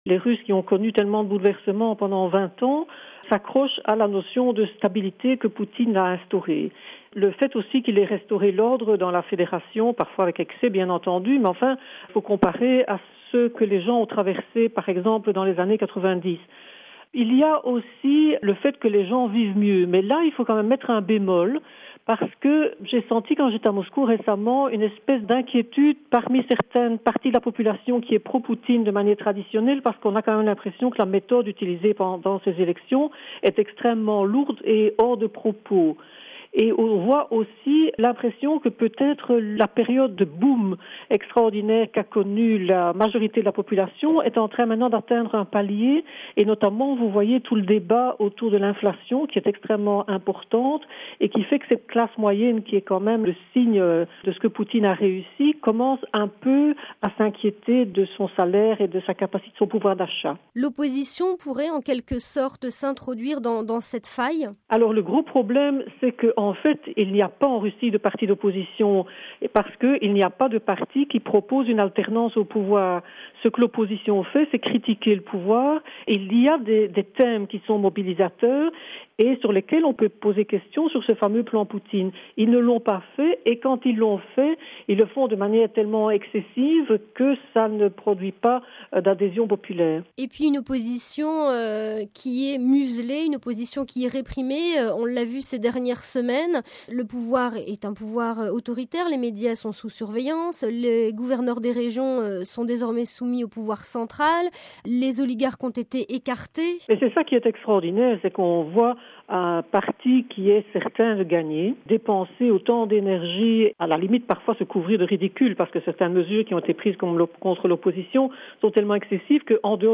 Dossier : Pourquoi les Russes aiment Vladimir Poutine